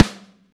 damped snare ff.wav